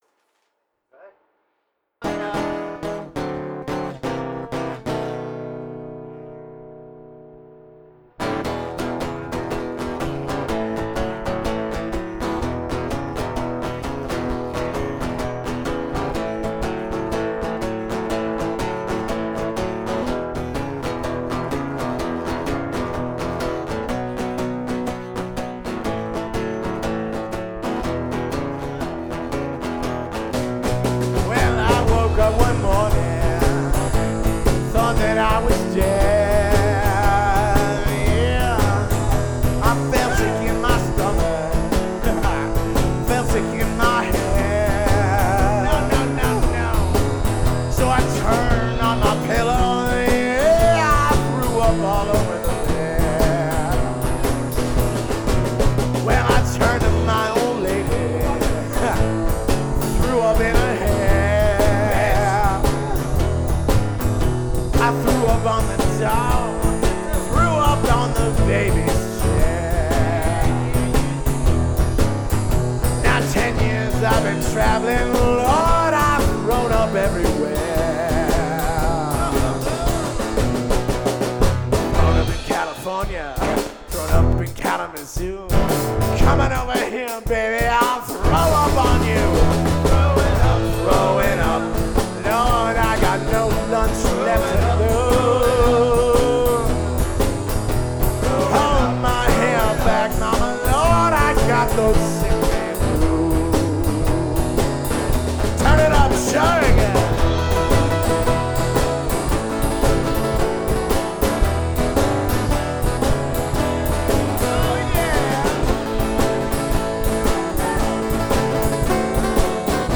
Vocals
Guitar
Drums & Studio